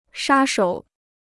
杀手 (shā shǒu) Free Chinese Dictionary